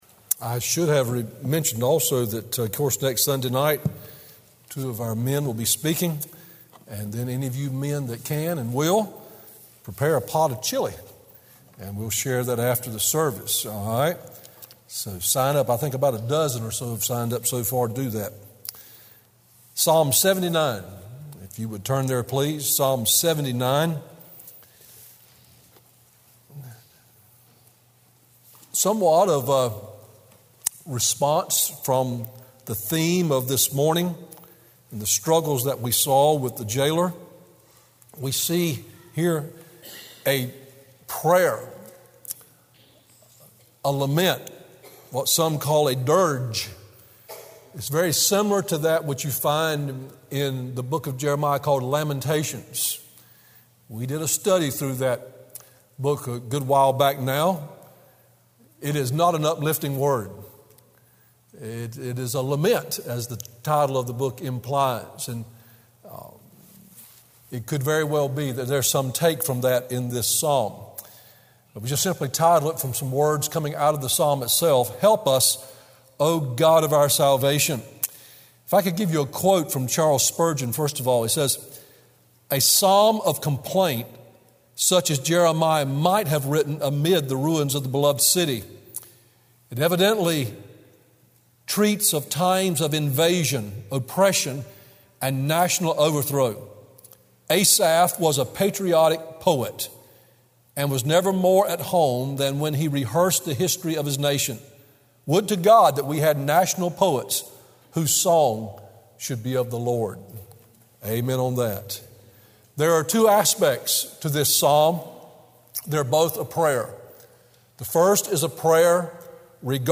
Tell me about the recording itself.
Morning WorshipColossians 1:27-29